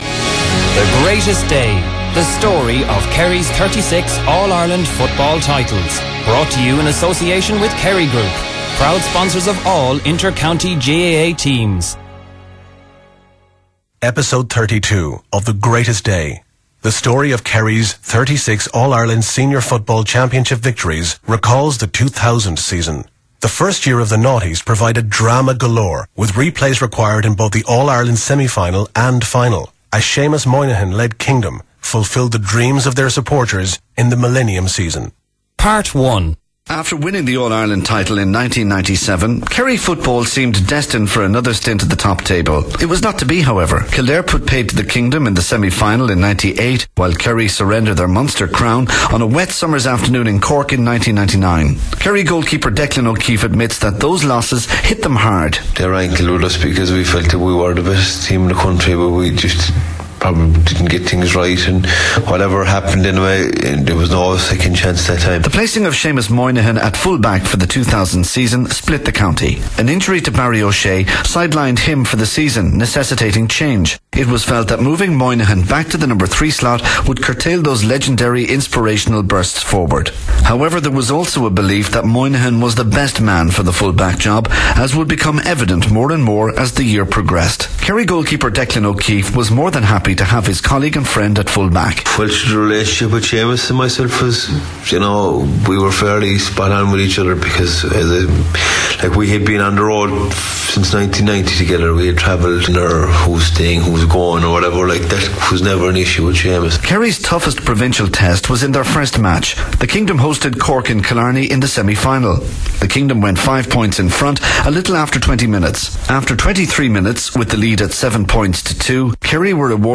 Radio Kerry - The Voice of the Kingdom * To download this audio to your computer in mp3 format, right click on this link and choose 'Save Target as' or 'Save Link as'.